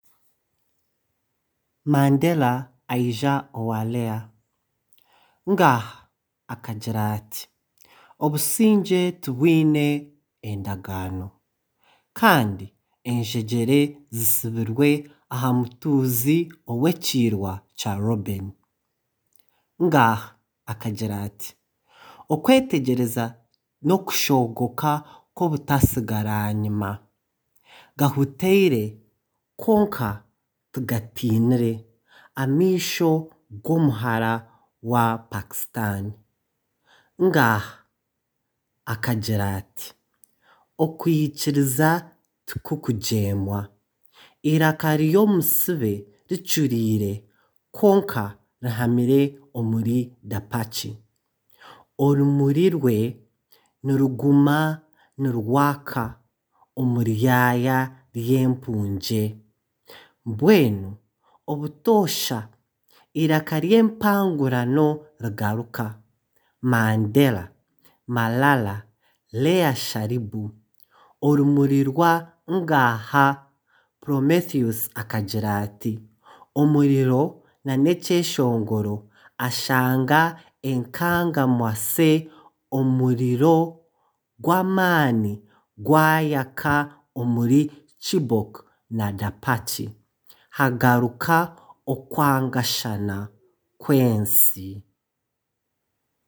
A reading of the poem in Runyankore